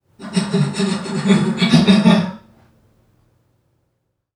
NPC_Creatures_Vocalisations_Robothead [84].wav